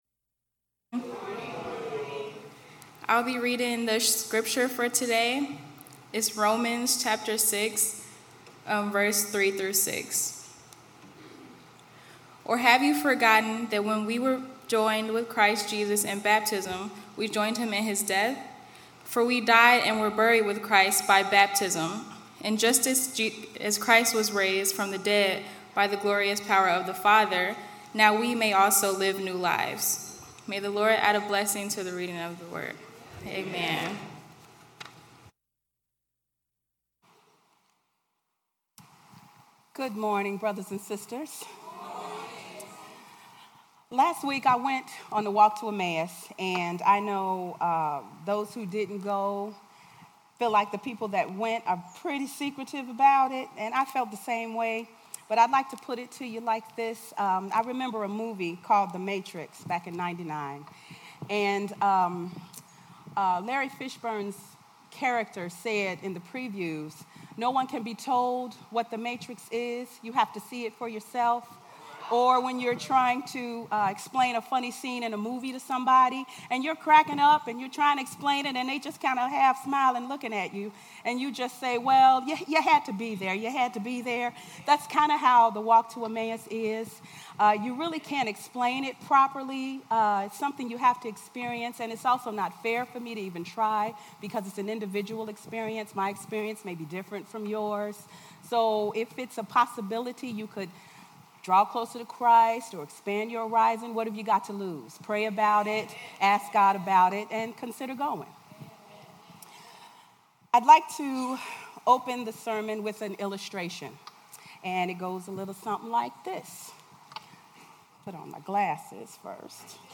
Worship Service 5/27/18